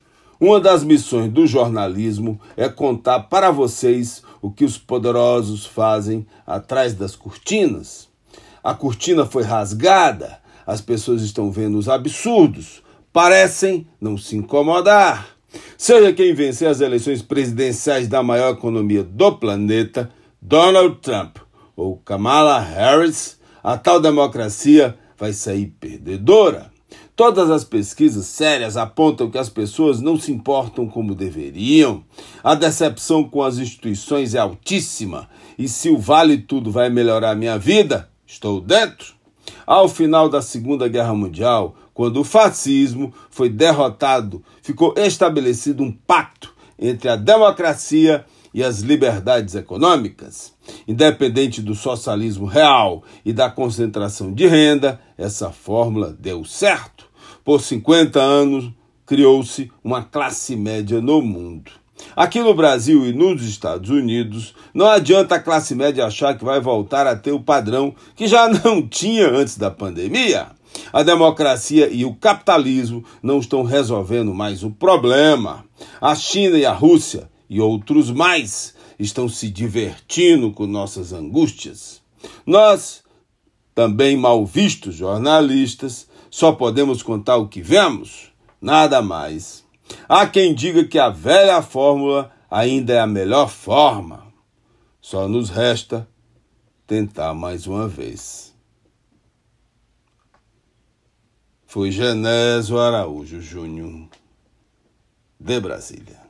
Comentário
direto de Brasília.